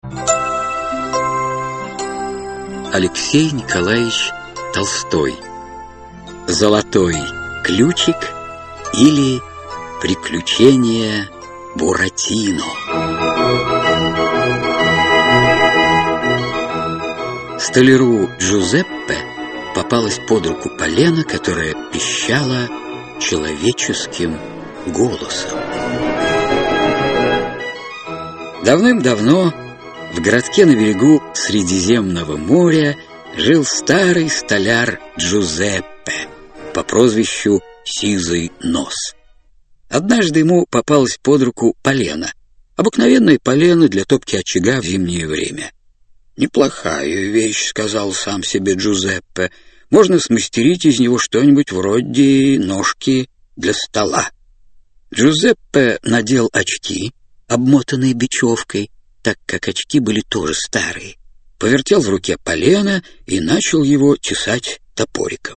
Аудиокнига Золотой ключик, или Приключения Буратино (спектакль) | Библиотека аудиокниг